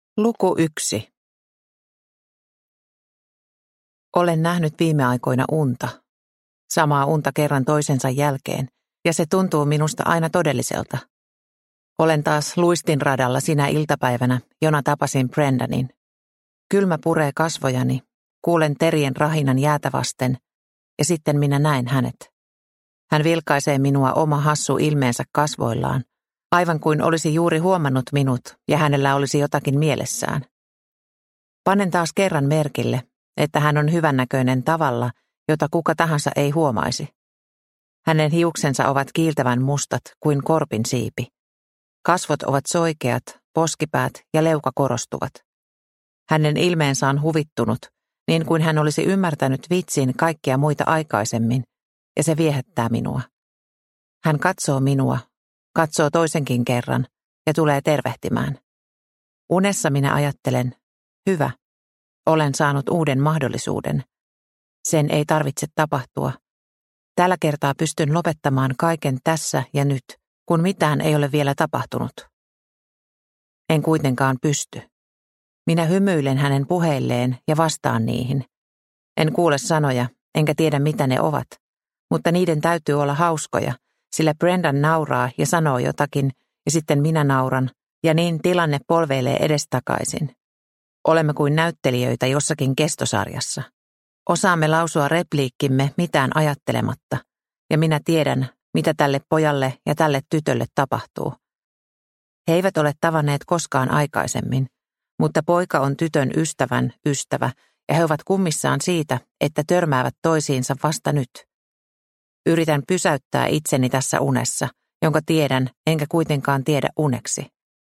Pedon hymy – Ljudbok – Laddas ner